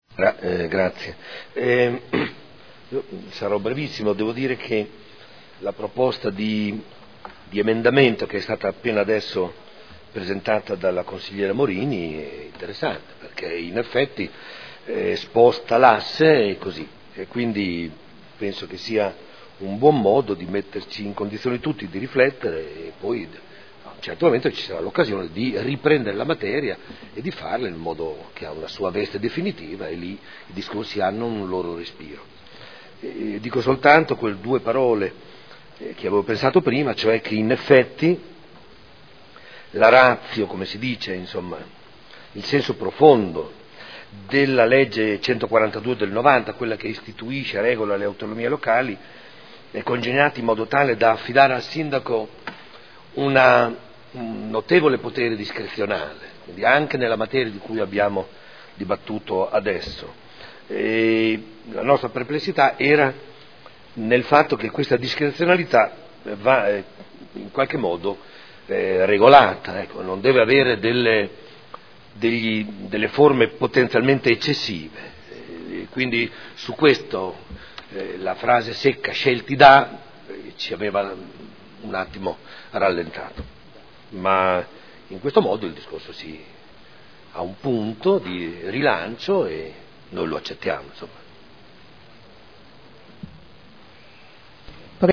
Seduta del 09/12/2015 Dibattito. Ordini del giorno.